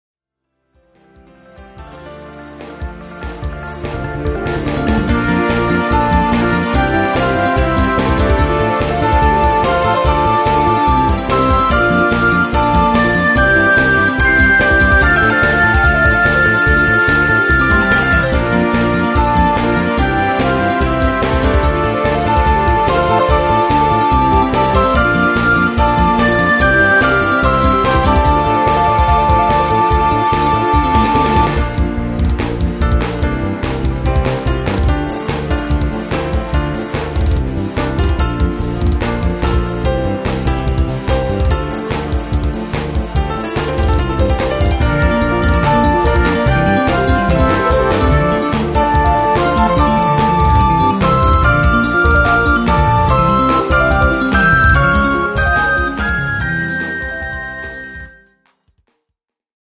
楽しい雰囲気が伝わればいいなぁ…。